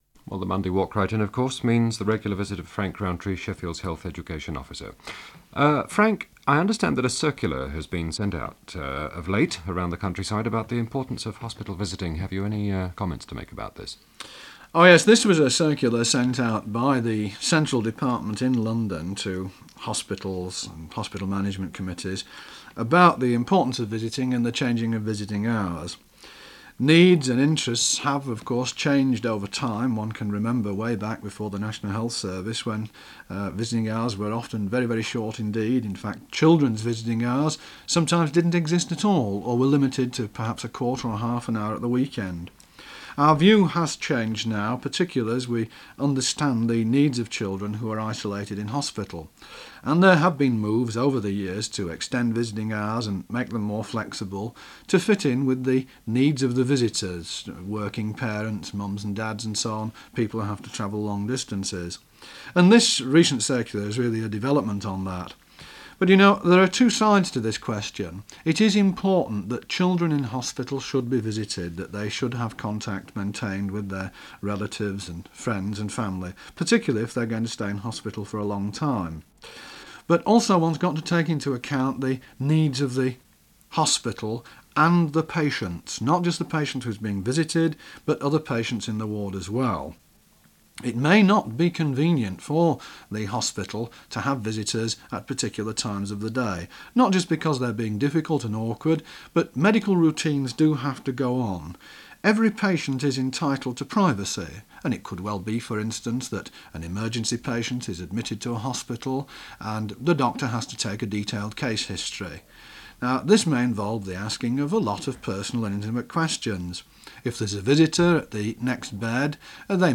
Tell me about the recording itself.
Sheffield : BBC Radio Sheffield, 1972.